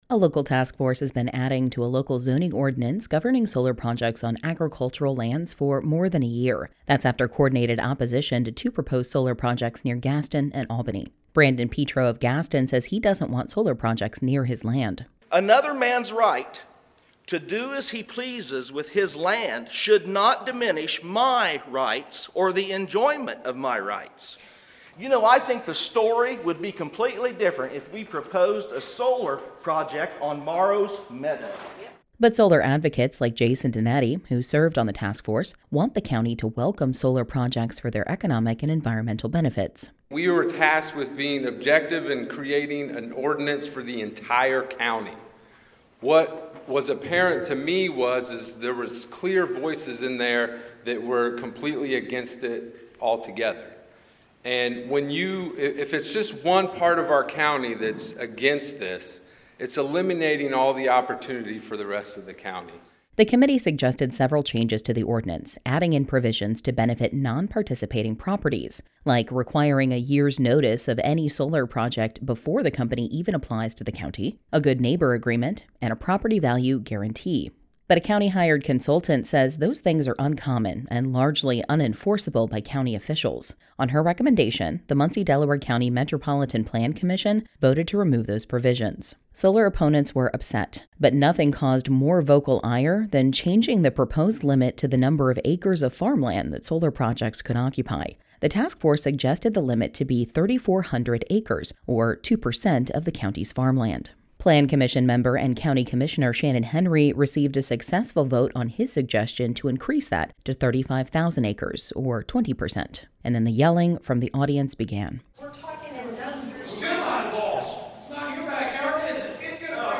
Both advocates and opponents of solar energy packed the Justice Center auditorium for a sometimes loud, angry meeting.
And then the yelling from the audience began.
Various audience members reacted as the plan commission president banged the gavel.